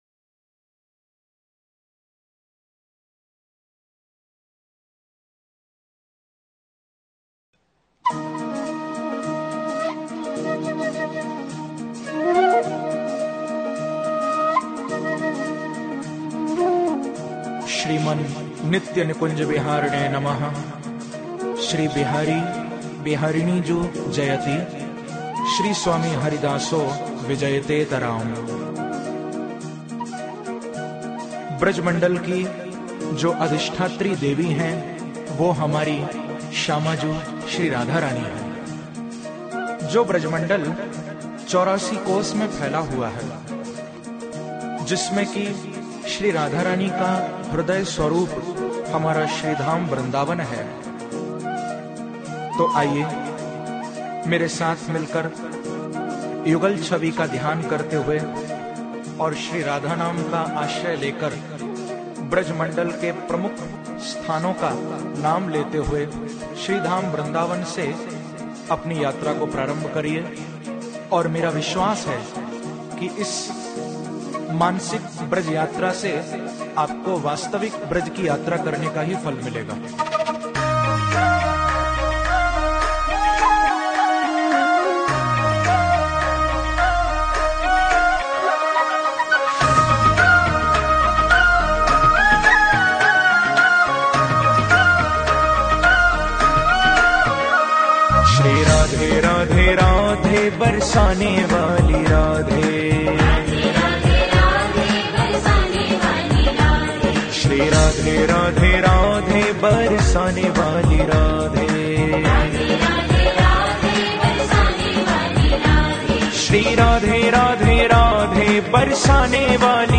मधुर भजन
brij-84-kosh-yatra-Radha-naam-sang.mp3